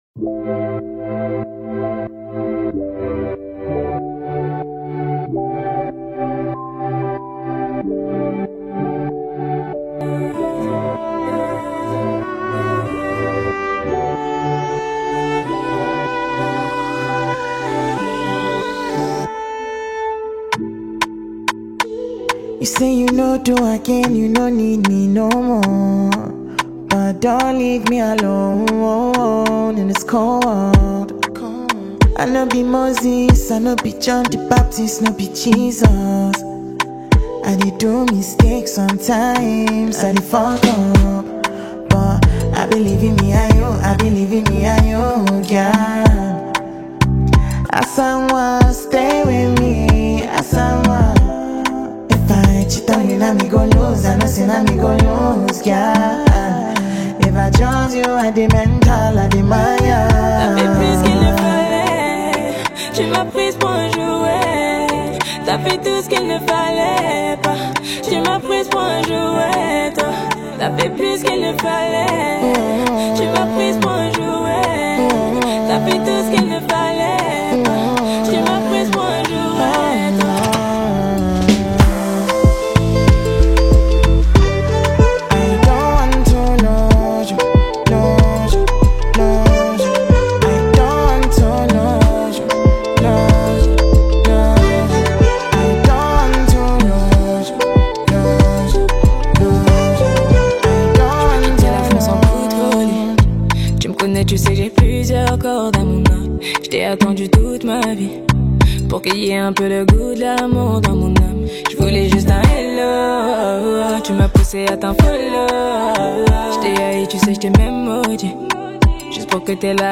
Nigerian melodious singer-producer
melodious vocals